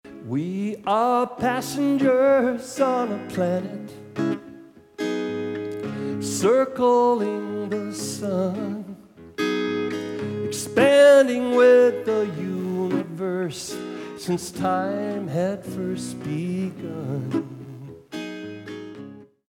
Original Song from Fundraiser Concert 2016